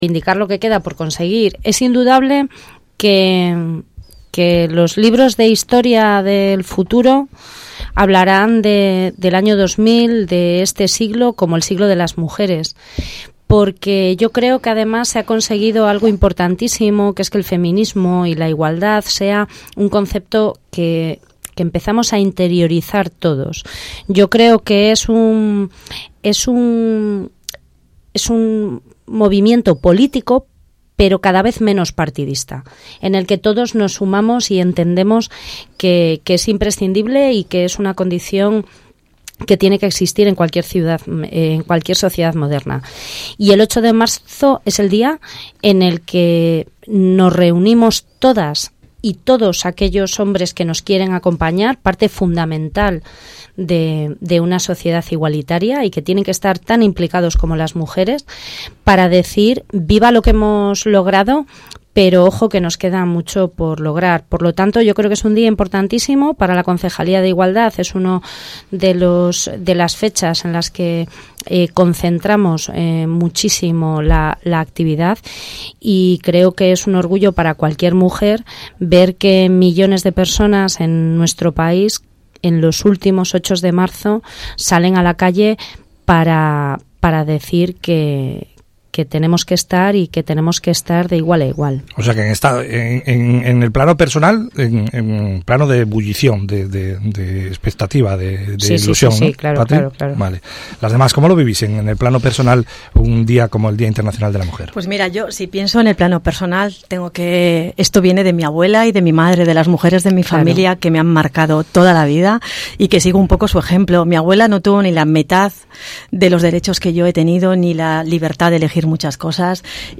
Hoy en Más de Uno Torrelavega – Tertulia sobre el Día Internacional de la Mujer